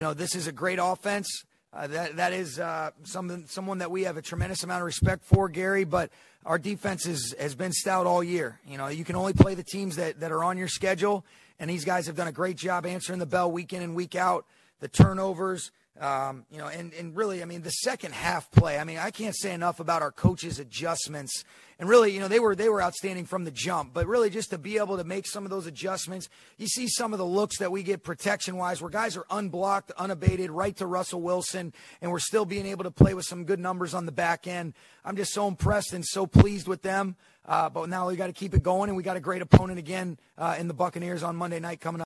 On the other side, Rams head coach Sean McVay said the defense played very well and they need to build off this victory to continue winning.